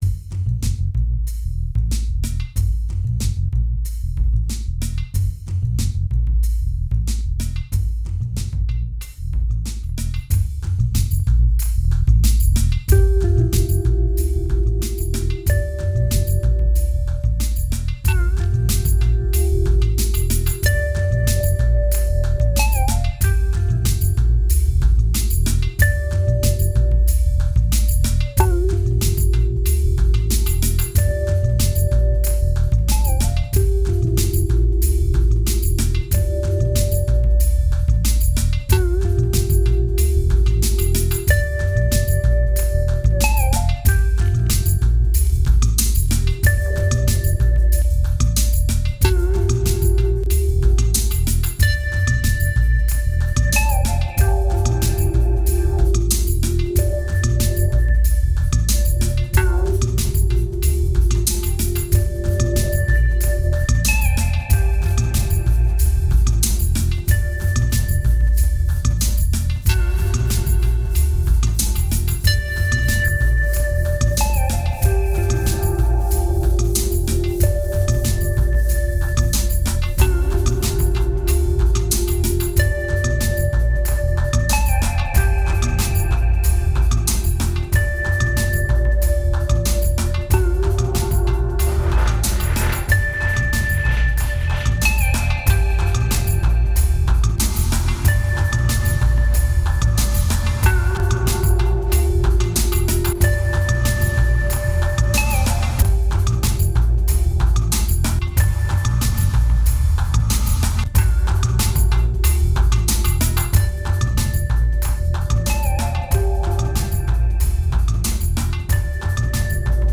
a dark album, fast and poorly recorded during late 2007